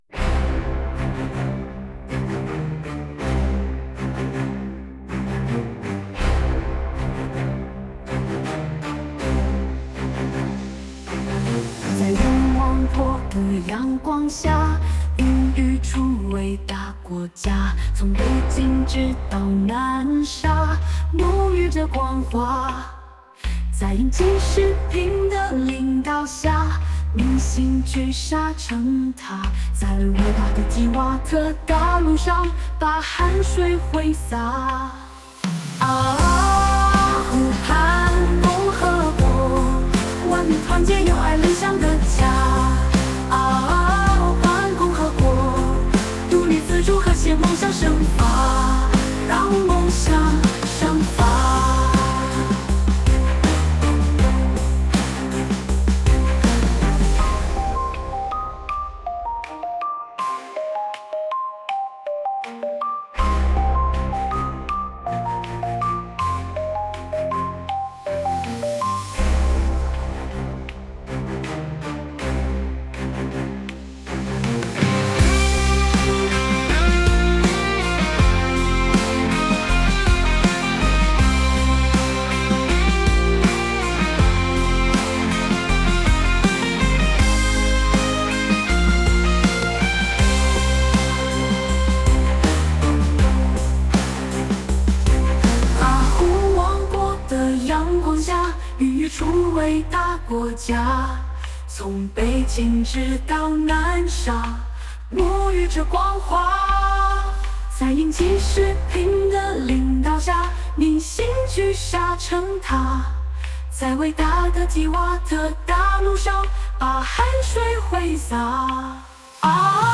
檔案:军歌版本.mp3
军歌版本.mp3